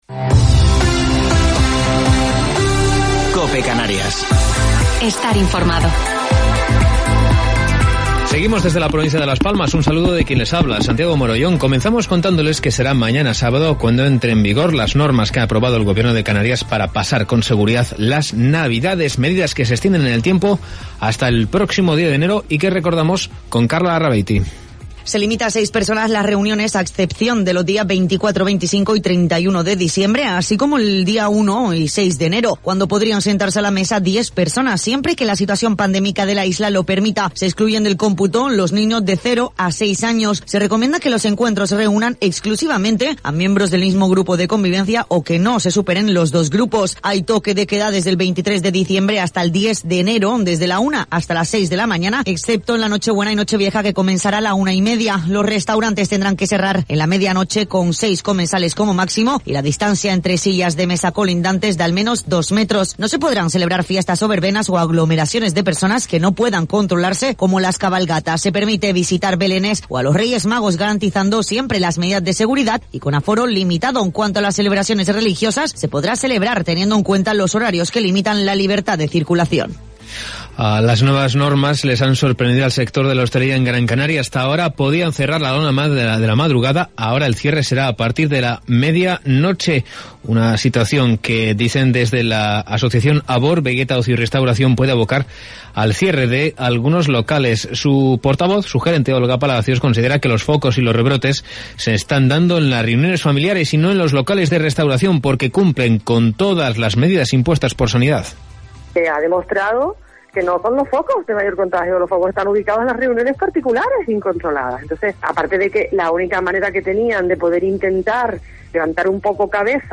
Informativo local 4 de Diciembre del 2020